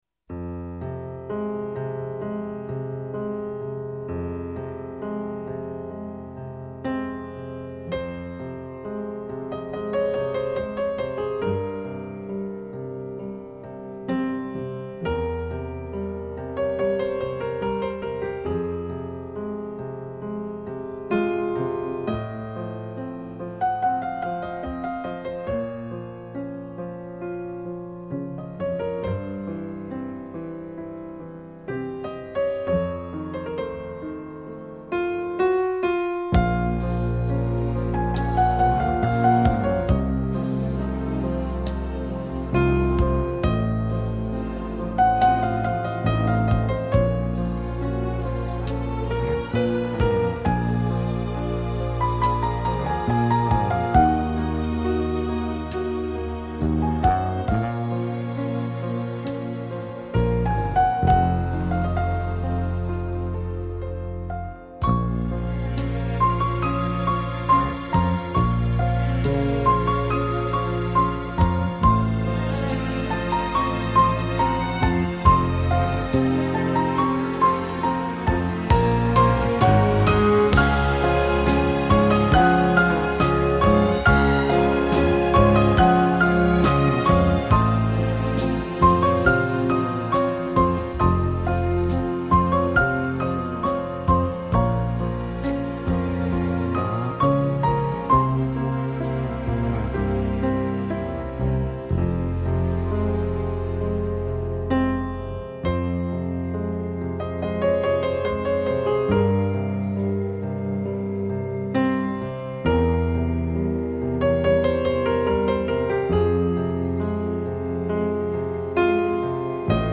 钢琴演奏